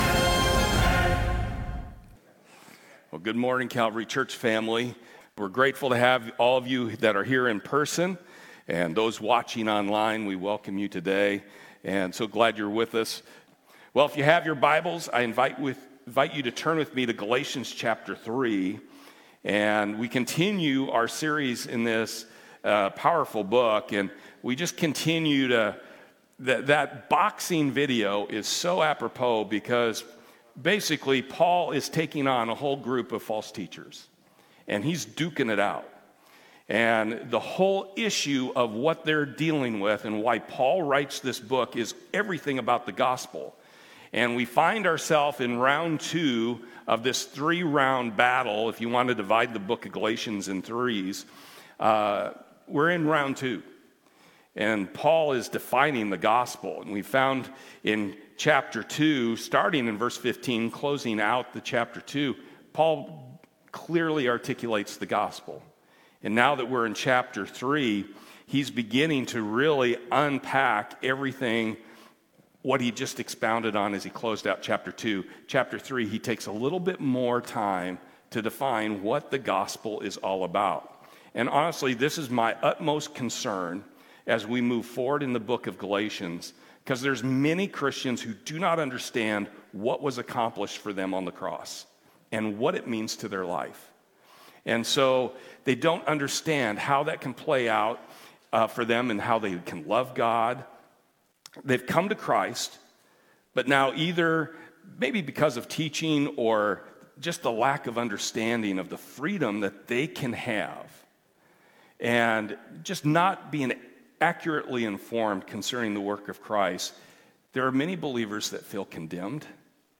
Series: The Book of Galatians Service Type: Sunday